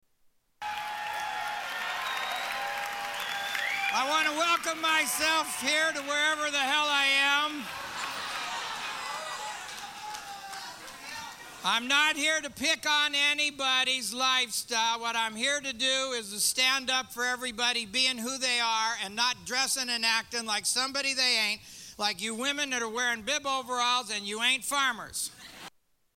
Stand-up for everybody
Category: Comedians   Right: Personal
Tags: Comedians Gallagher Smashing Watermelons Sledge-o-matic Prop comic